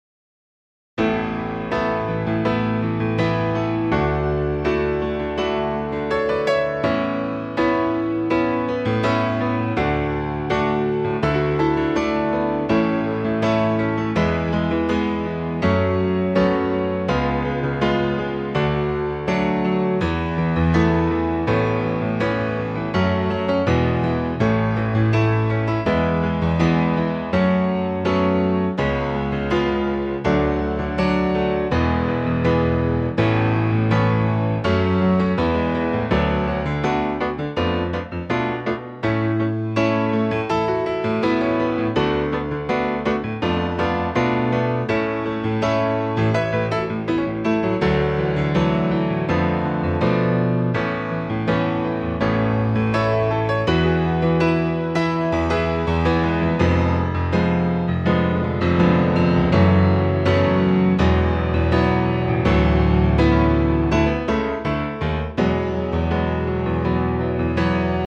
key - A - vocal range - E to F#
A punchy piano only arrangement
in a lower key. (1 and 1/2 down).